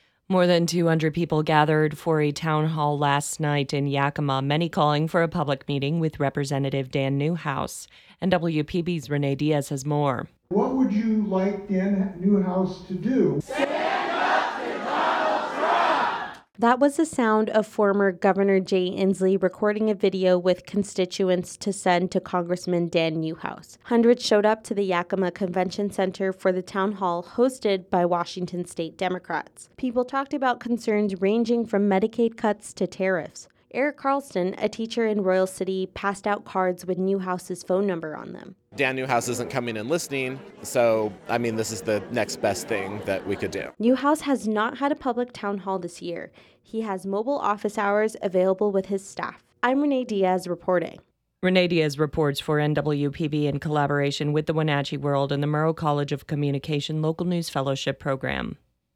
WEB_townhall_WRAP.wav